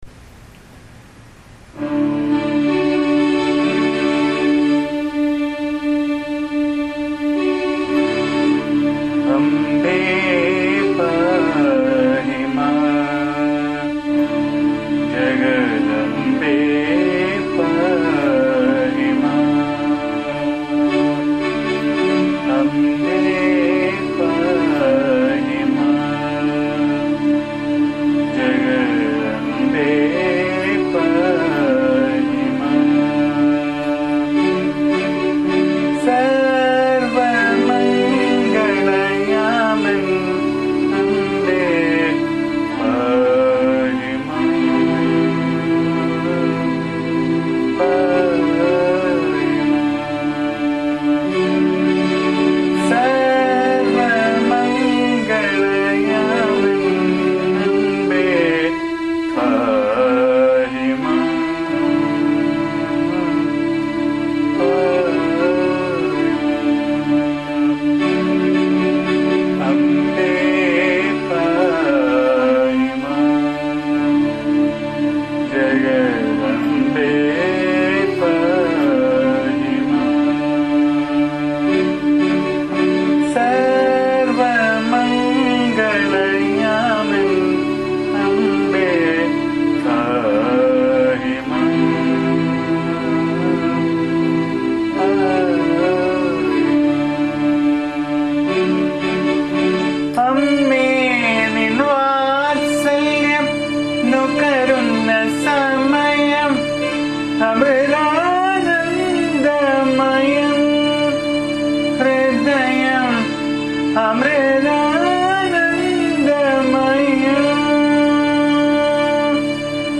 AMMA's bhajan song